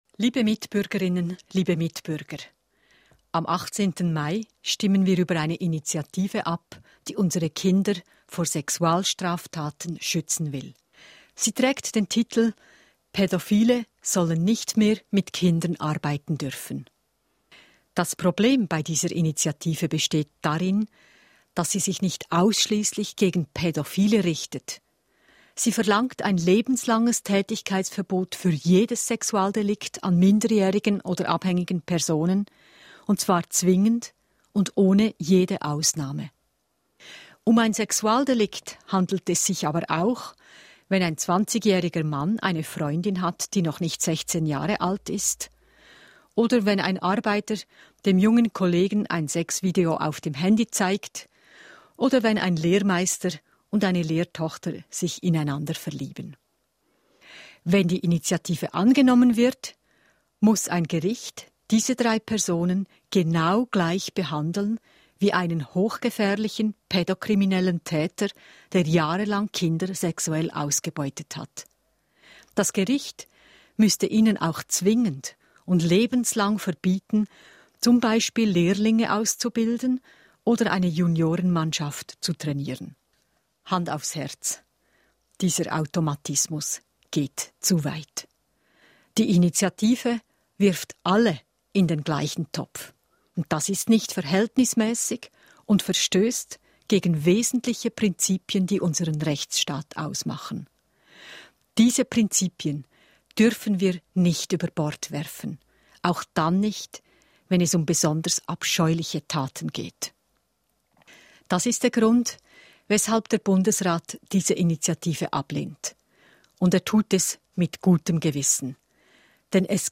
Bundesrätin Simonetta Sommaruga
Stellungnahme des Bundesrates